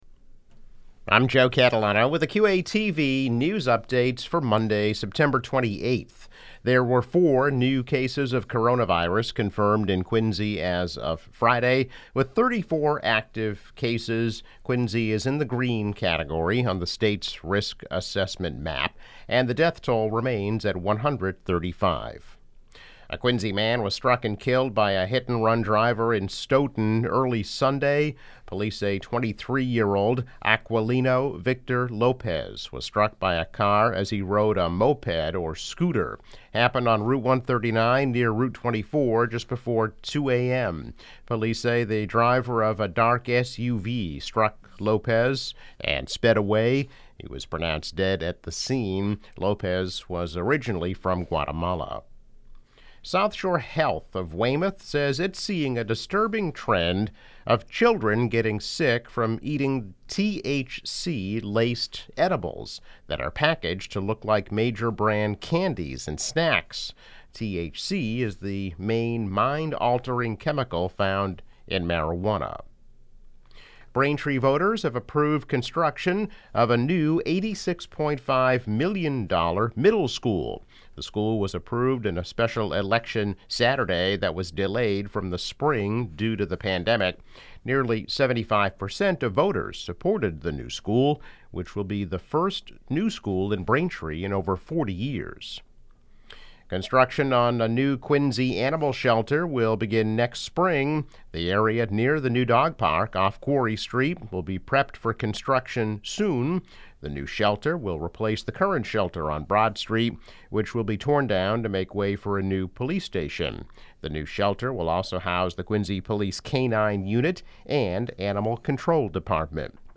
News Update - September 28, 2020